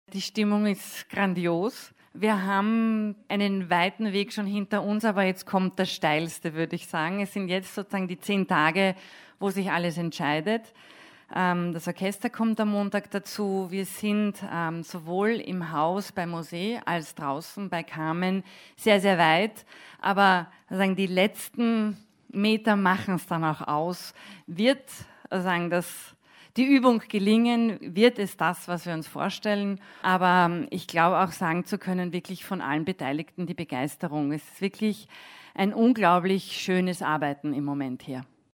Pressetag 2017 Radio-Feature 1